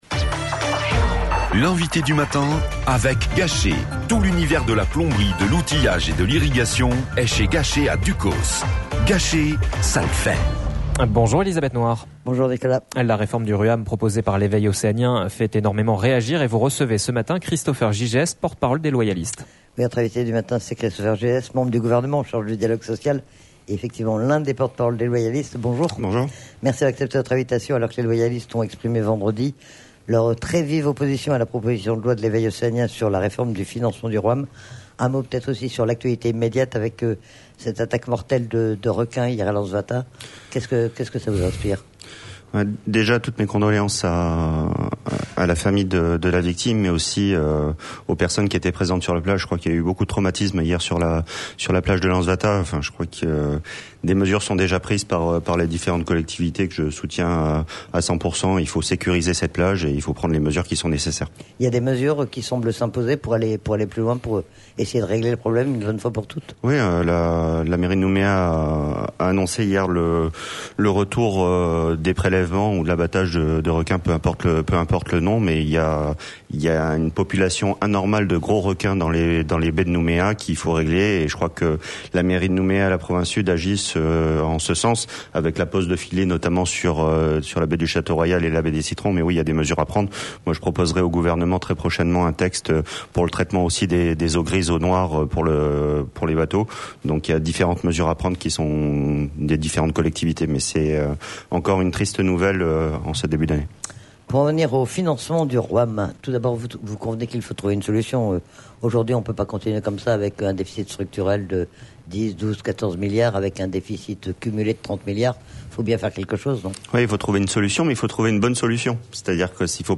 Christopher Gygès, porte-parole des Loyalistes